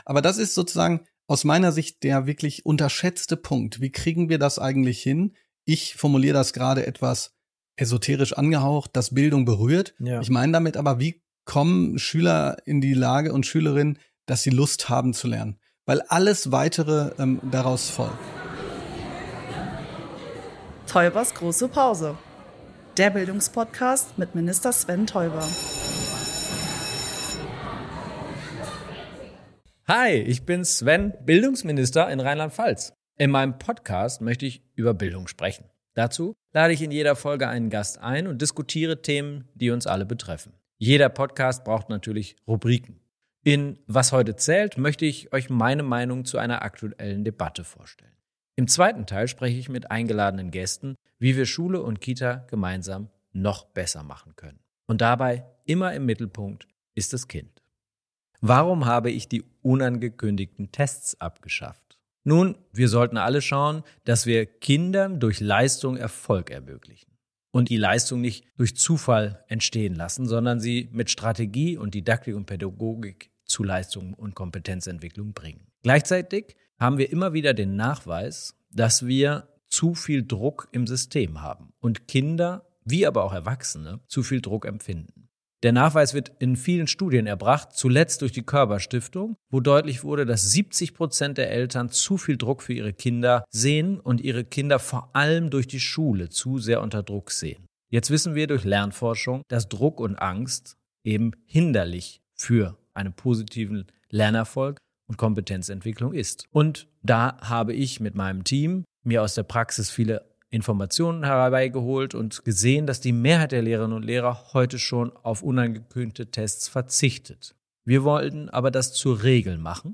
Bildungsminister Sven Teuber spricht mit spannenden Gästen über die Zukunft der Bildung.